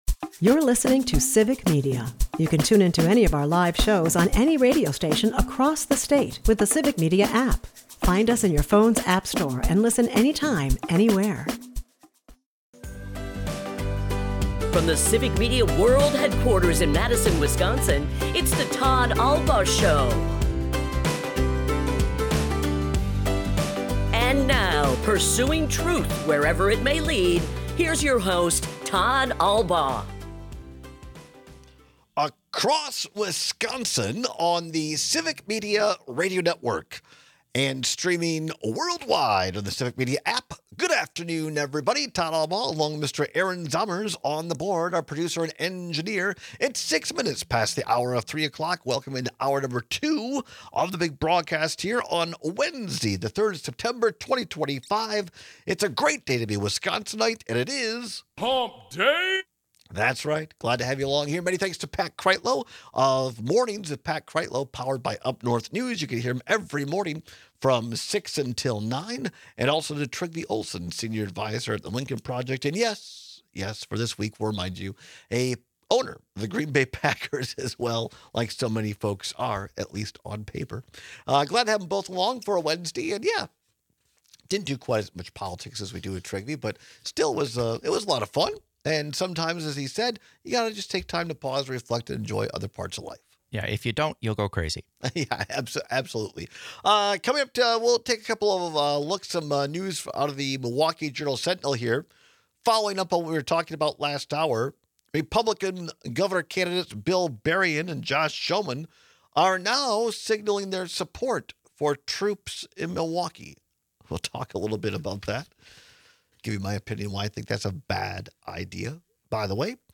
We take your calls and texts with strong cases for both sides.&nbsp
airs live Monday through Friday from 2-4 pm across Wisconsin